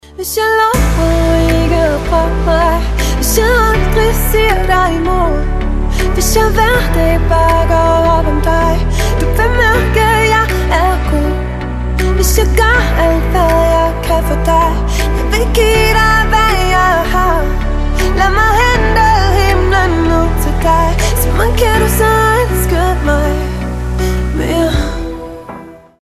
• Качество: 256, Stereo
поп
женский вокал
dance